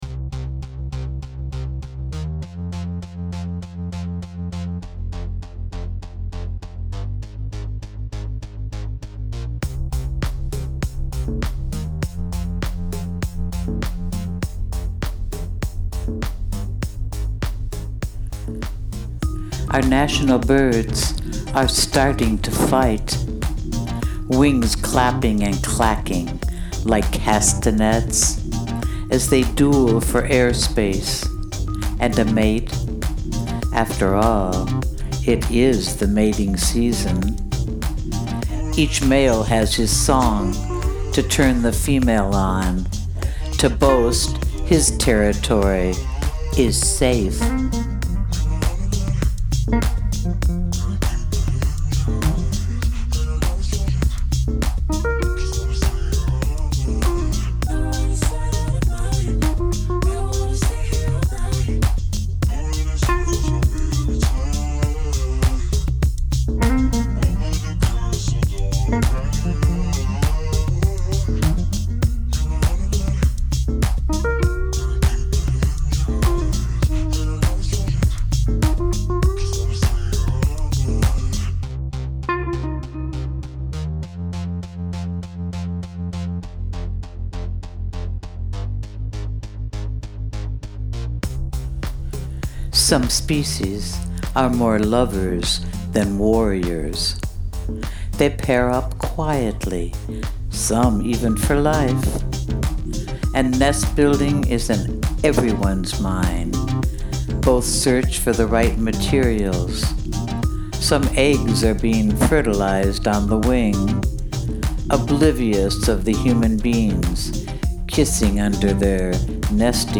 and recite a poem to it:)  Since I always say yes to my muse….I did:)
Love this poem and how you’ve recited it so beautifully.